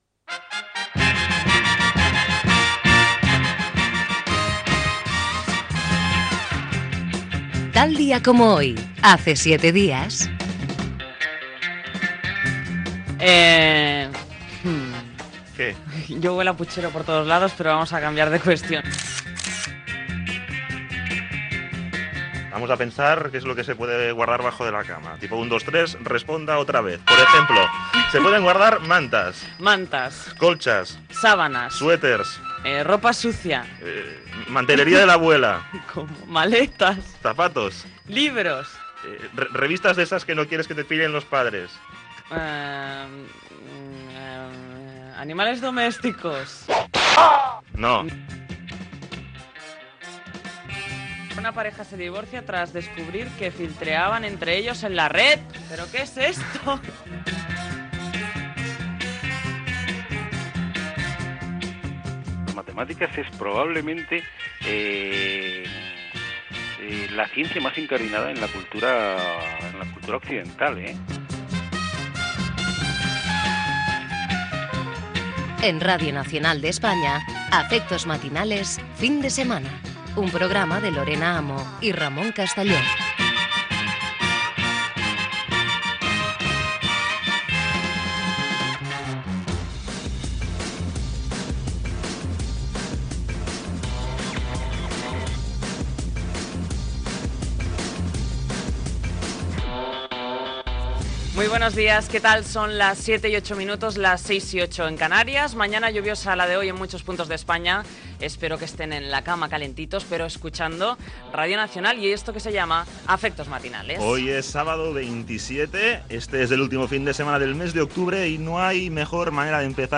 Indicatiu del programa, hora, presentació, sumari i indicatiu.
Entrevista a la presentadora Nuria Roca que ha publicat el llibre "Sexualmente"